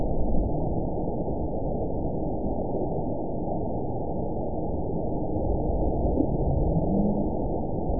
event 917882 date 04/19/23 time 22:59:28 GMT (2 years ago) score 7.65 location TSS-AB04 detected by nrw target species NRW annotations +NRW Spectrogram: Frequency (kHz) vs. Time (s) audio not available .wav